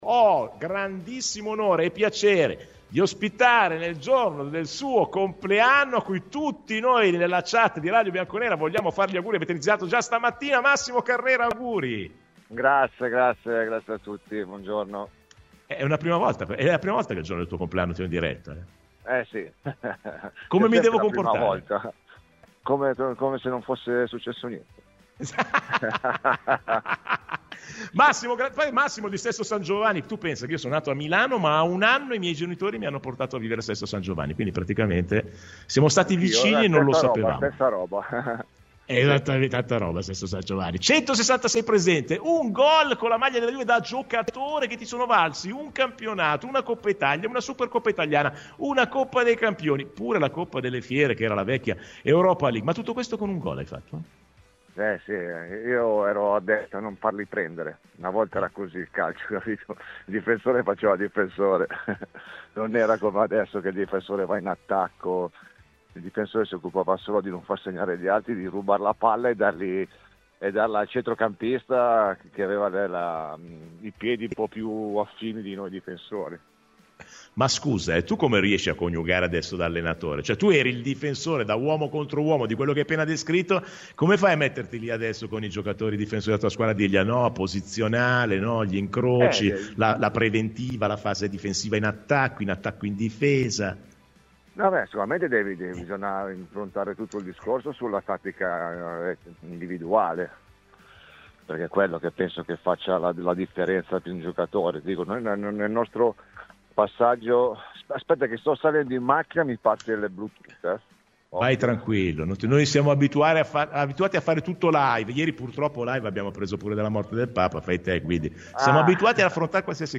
Intervenuto ai microfoni di Radio Bianconera, l’ex difensore e tecnico juventino ha aperto con entusiasmo alla possibilità di un clamoroso ritorno a Torino.